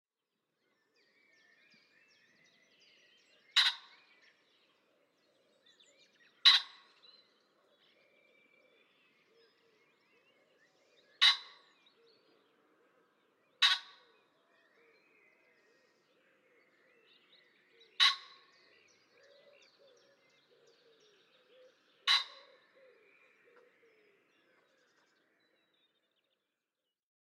PFR10812, 1-11, 150507, Common Pheasant Phasianus colchicus, song
Aylmerton, UK, Telinga parabolic reflector